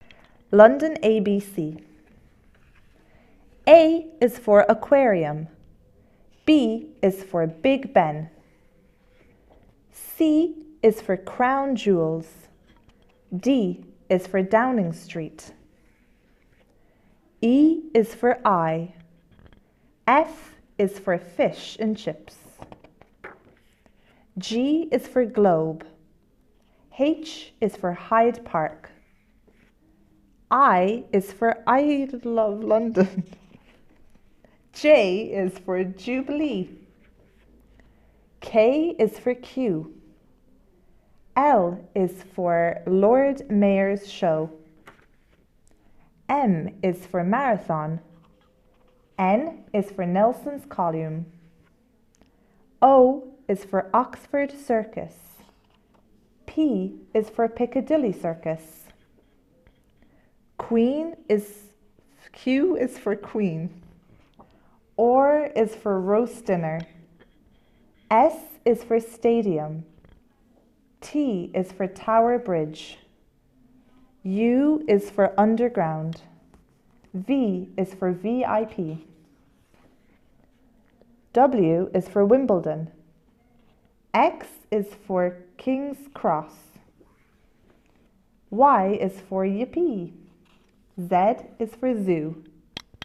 London ABC irlandais
london-abc-irlandais.mp3